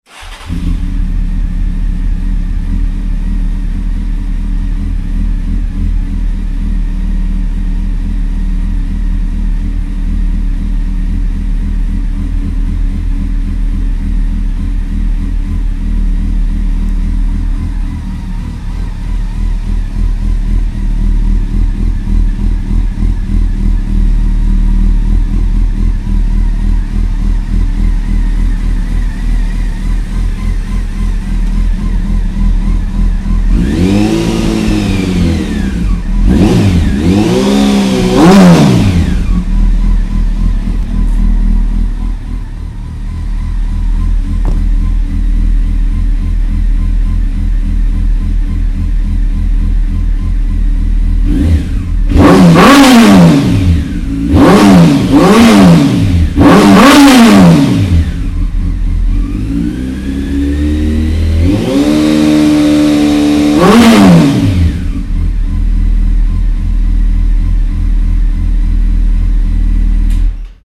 Sweet_Sound_of_SATO_Slip-ons.mp3